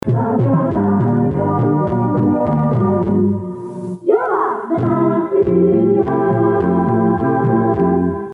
Indicatiu cantat de l'emissora.